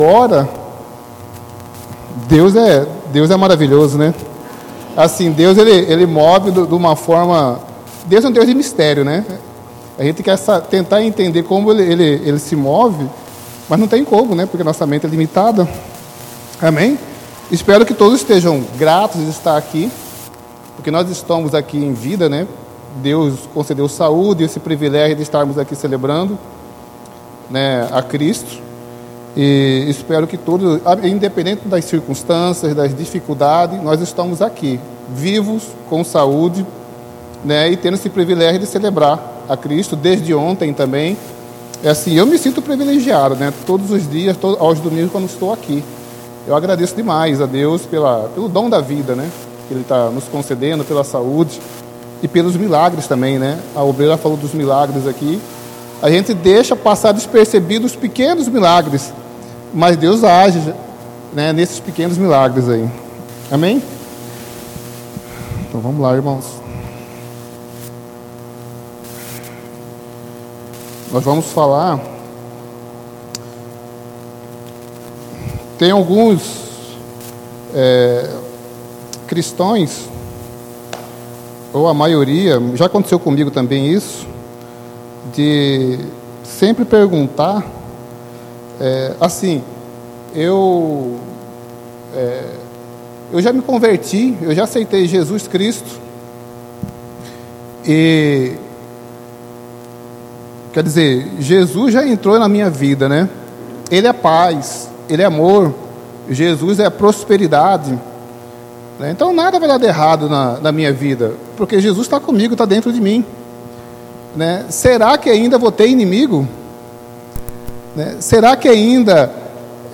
Culto de Celebração